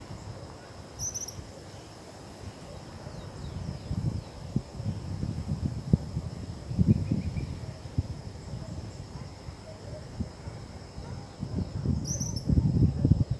Tropical Kingbird (Tyrannus melancholicus)
Province / Department: Entre Ríos
Detailed location: Villa Zorraquin
Condition: Wild
Certainty: Observed, Recorded vocal